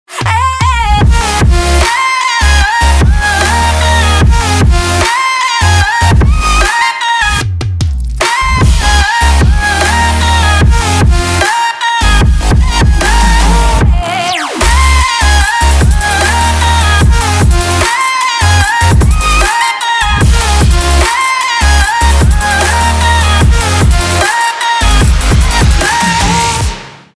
• Качество: 320, Stereo
громкие
Electronic
без слов
future bass